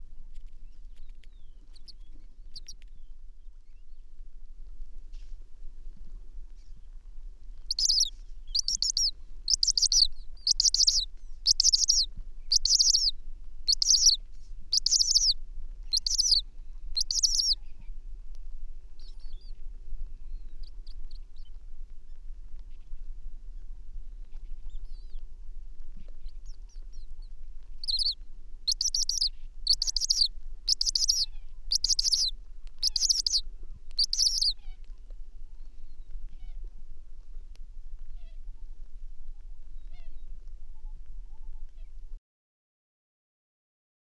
Snowy Owl
In CD3-52, you can hear two loud series of chitter calls, a sign that the young were cold or stressed.
Chittering of nestlings.
52-Snowy-Owl-Chittering-Of-Nestlings.wav